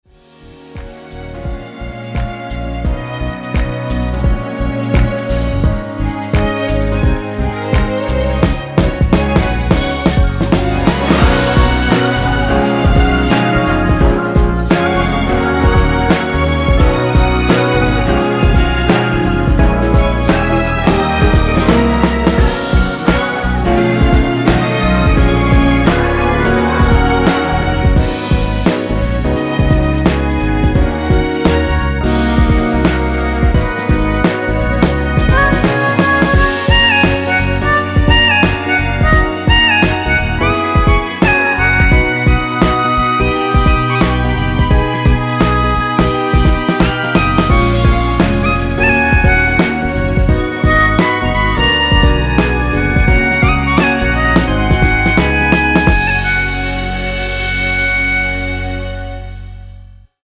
엔딩이 페이드 아웃이라서 노래하기 편하게 엔딩을 만들어 놓았으니 미리듣기 확인하여주세요!
원키에서(-2)내린 코러스 포함된 MR입니다.
Gb
앞부분30초, 뒷부분30초씩 편집해서 올려 드리고 있습니다.
중간에 음이 끈어지고 다시 나오는 이유는